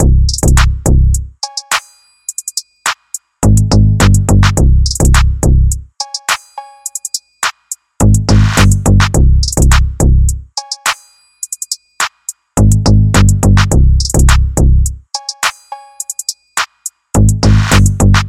Blueface Westcoast Drum and BASS Loop
描述：西海岸的鼓声，如blueface或sob x rbe
Tag: 105 bpm Rap Loops Drum Loops 3.08 MB wav Key : Unknown FL Studio